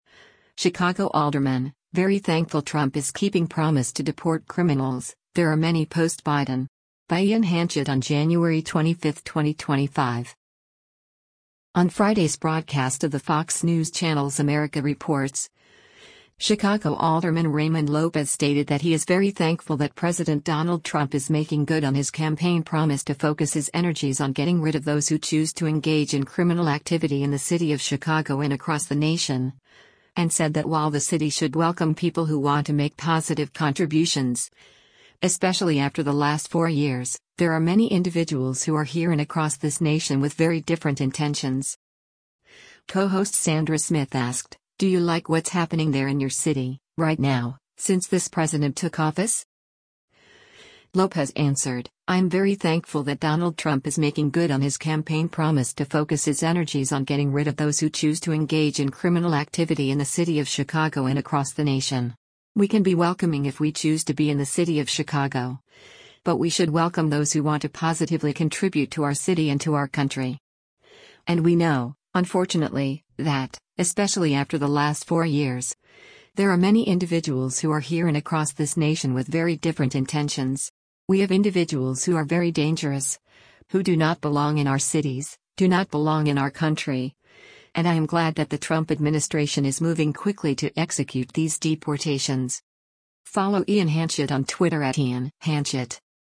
On Friday’s broadcast of the Fox News Channel’s “America Reports,” Chicago Alderman Raymond Lopez stated that he is “very thankful” that President Donald Trump “is making good on his campaign promise to focus his energies on getting rid of those who choose to engage in criminal activity in the city of Chicago and across the nation.”
Co-host Sandra Smith asked, “Do you like what’s happening there in your city, right now, since this President took office?”